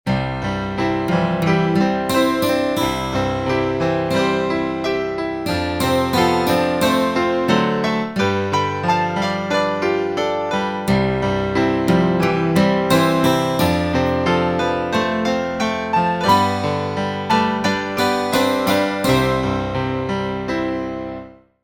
使用楽器はピアノとアコースティックギターで演奏してあります。